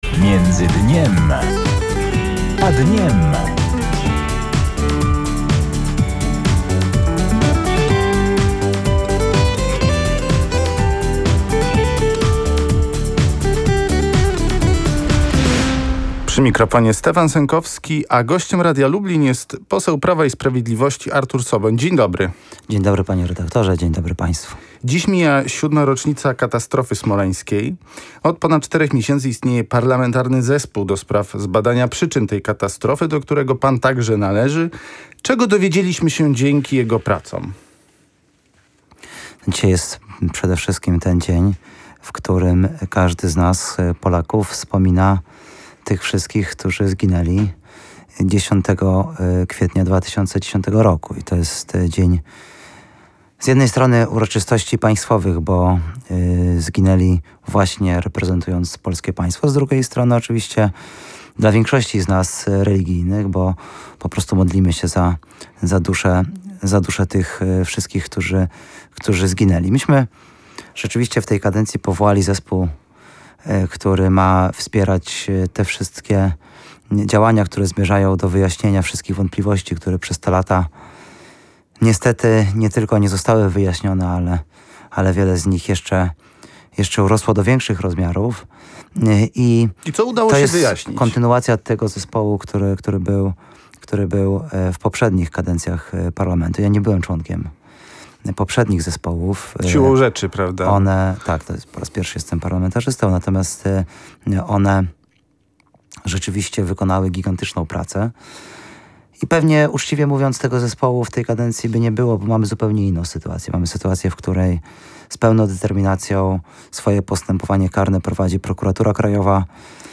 – Takie bardzo mało precyzyjne stawianie sprawy jest według mnie źródłem kłopotów – mówi Artur Soboń (na zdj.), poseł Prawa i Sprawiedliwości.